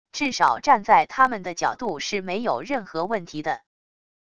至少站在他们的角度是没有任何问题的wav音频生成系统WAV Audio Player